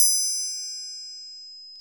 PRC TRIANG09.wav